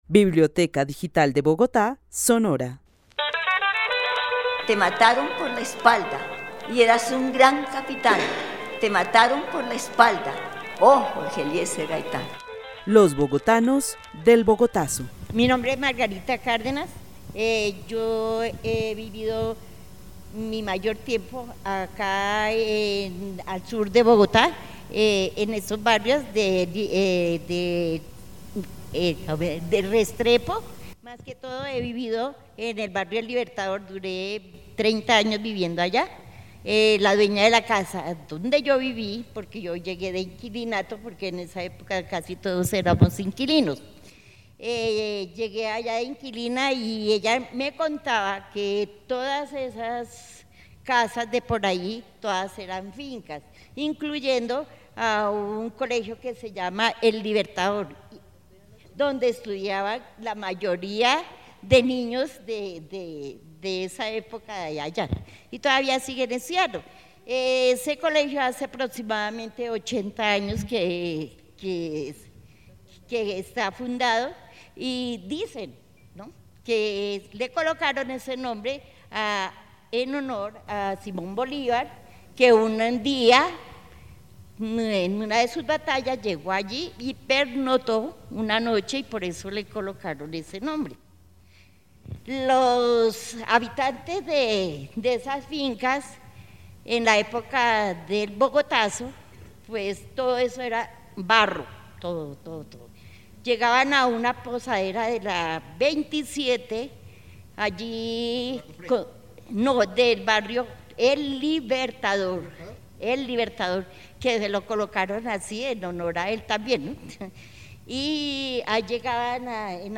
Narración oral sobre la vida al sur de Bogotá, en los barrios Restrepo y El Libertador entre los años 40 y los años 50. El testimonio fue grabado en el marco de la actividad "Los bogotanos del Bogotazo" con el club de adultos mayores de la Biblioteca Carlos E. Restrepo.